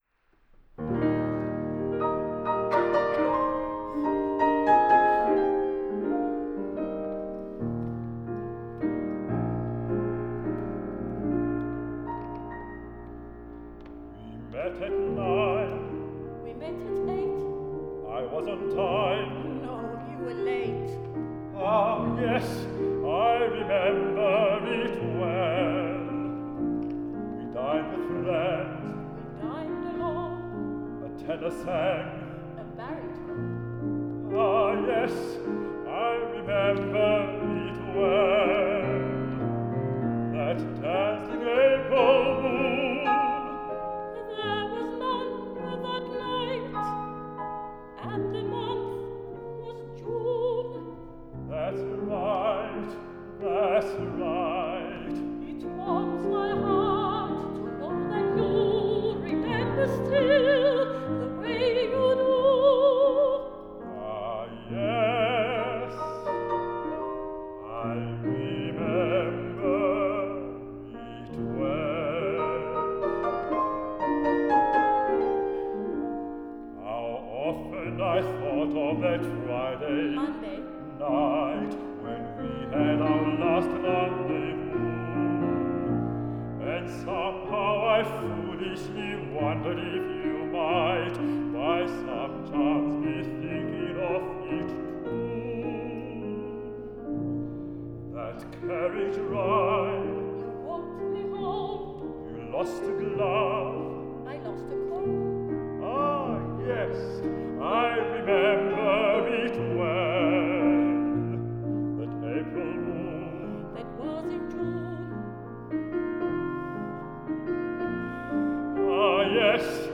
Lunch hour concert 2019